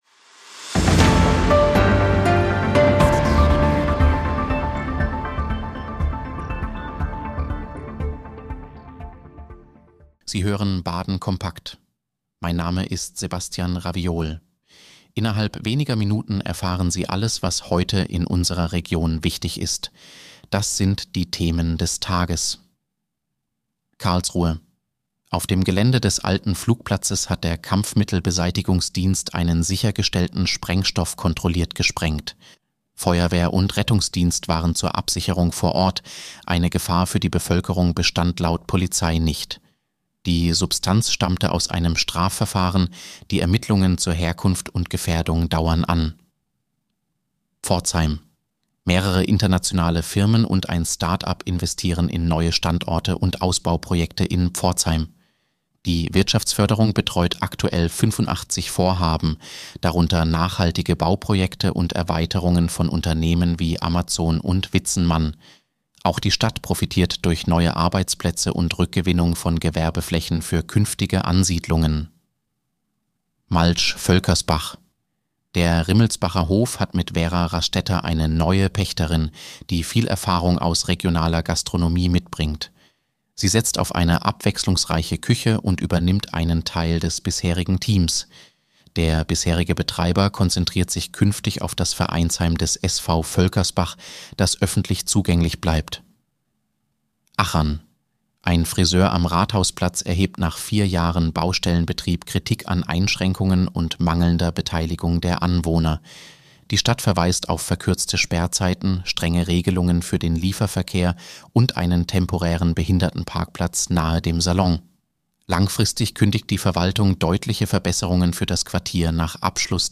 Nachrichtenüberblick: Sprengstoff-Fund bei Polizei in Karlsruhe